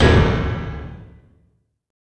Index of /90_sSampleCDs/USB Soundscan vol.24 - Industrial Loops [AKAI] 1CD/Partition E/03-BD KIT